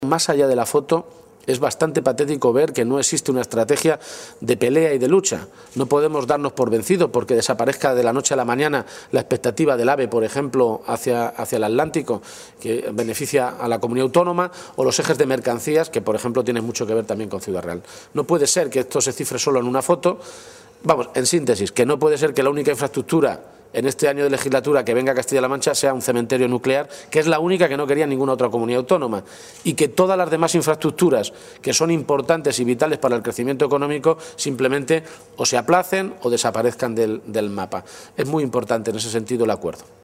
García-Page hacía estas declaraciones en un desayuno informativo con representantes de los medios de comunicación en Ciudad Real, donde ha abordado diferentes asuntos de actualidad.
Cortes de audio de la rueda de prensa